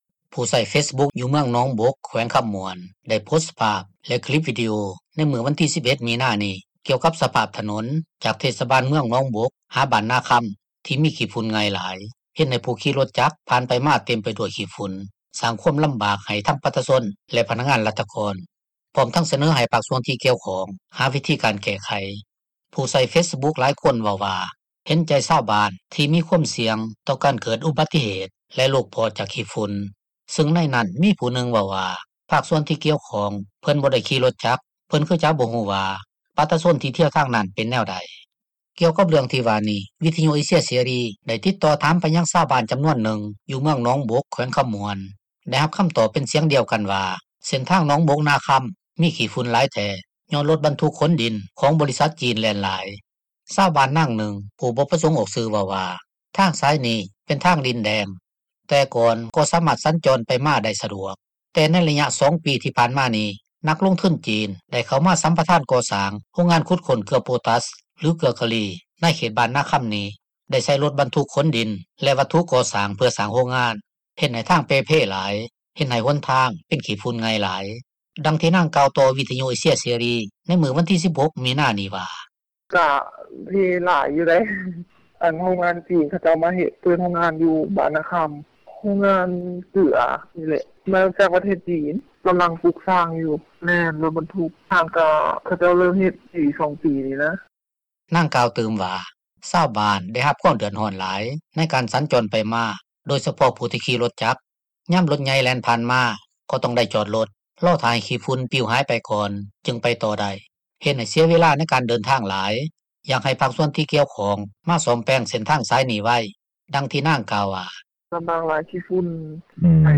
ຊາວບ້ານນາງນຶ່ງ ຜູ້ບໍ່ປະສົງອອກຊື່ ເວົ້າວ່າ ທາງສາຍນີ້ ເປັນທາງດິນແດງ ແຕ່ກ່ອນກໍ່ສາມາດສັນຈອນໄປມາໄດ້ສະດວກ, ແຕ່ໃນໄລຍະ 2 ປີ ທີ່ຜ່ານມານີ້ ນັກລົງທຶນຈີນ ໄດ້ເຂົ້າມາສັມປະທານກໍ່ສ້າງ ໂຮງງານຂຸດຄົ້ນເກືອໂປຕາສ ຫຼື ເກືອກາລີ ໃນເຂດບ້ານນາຄຳນີ້, ໄດ້ໃຊ້ຣົຖບັນທຸກຂົນດິນ ແລະວັສດຸກໍ່ສ້າງ ເພື່ອສ້າງໂຮງງານ, ເຮັດໃຫ້ຫົນທາງເພຫລາຍ ເປັນຂີ້ຝຸ່ນໄງ່ຫຼາຍ ດັ່ງທີ່ນາງກ່າວຕໍ່ວິທຍຸເອເຊັຽເສຣີ ໃນມື້ວັນທີ 16 ມີນານີ້ວ່າ: